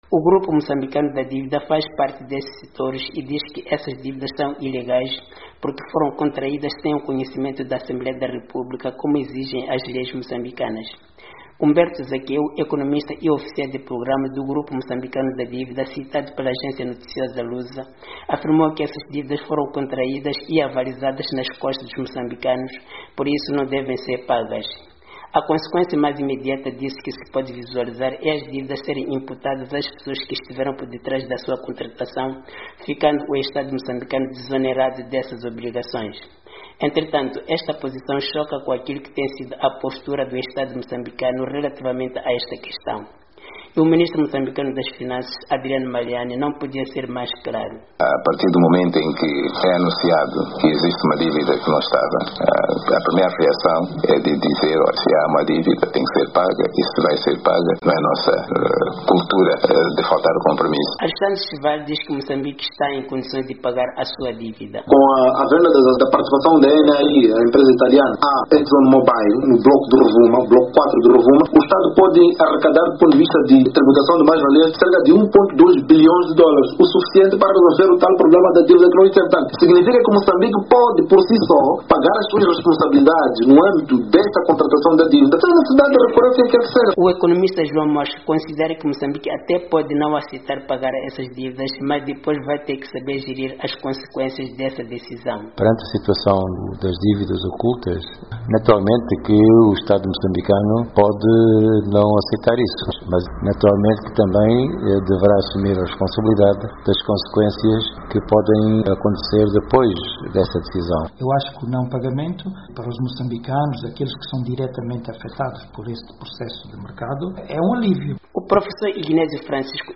Debate: deve ou não o Estado moçambicano pagar as dívidas